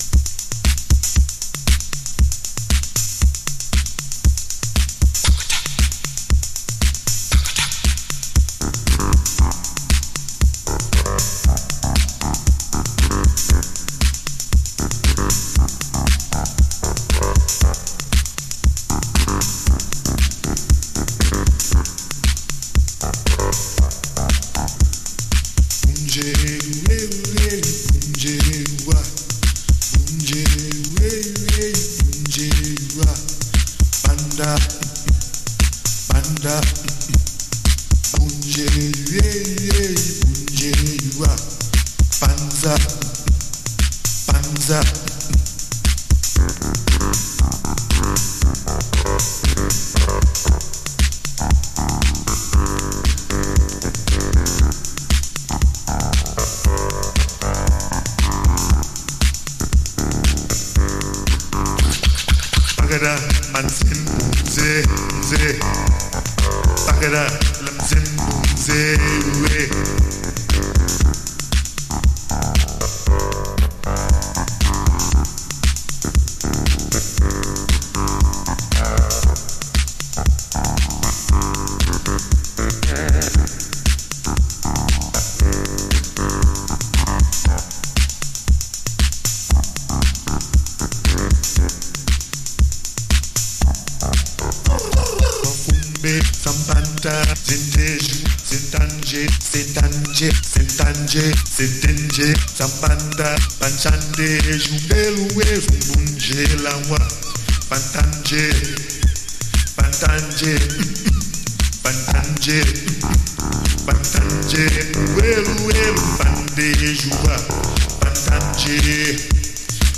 Love Chant Version